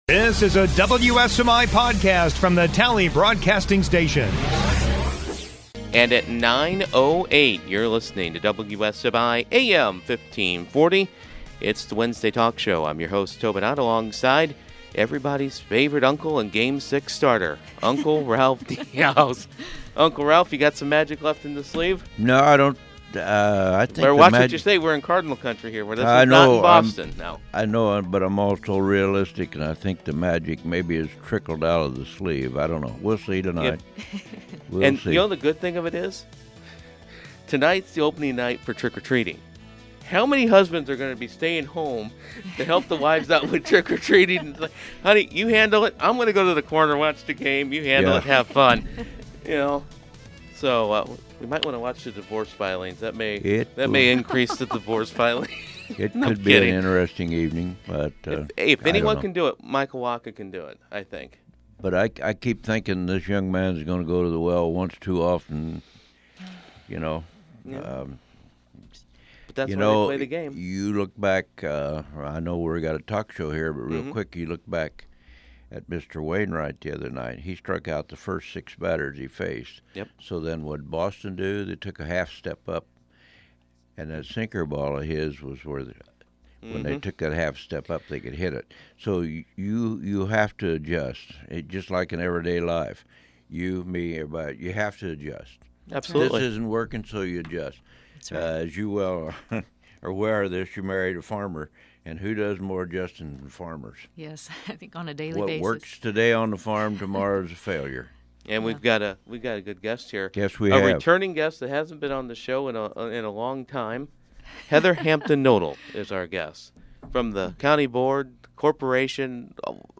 Wednesday Morning Talk Show